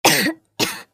女性の咳 着信音の試聴とダウンロード